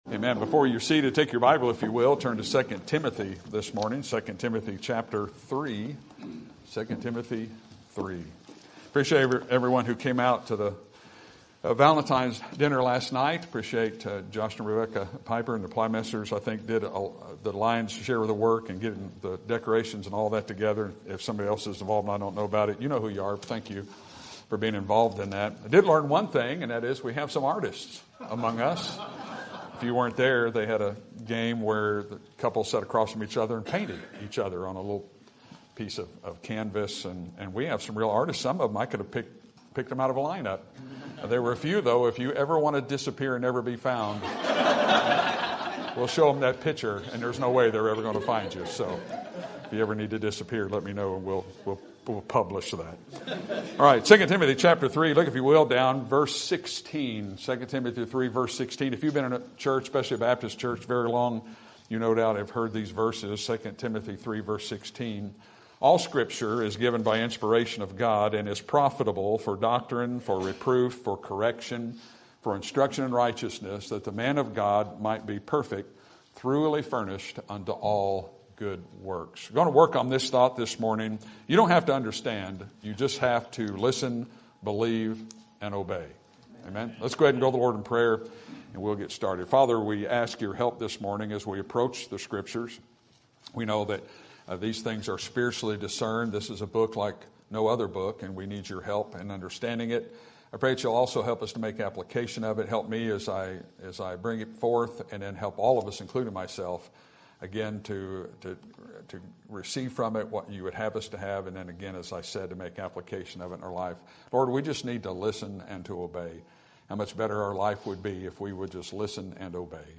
Sermon Topic: General Sermon Type: Service Sermon Audio: Sermon download: Download (22.55 MB) Sermon Tags: II Timothy Word Obey Listen